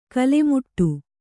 ♪ kalemuṭṭu